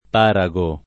parago [ p # ra g o ]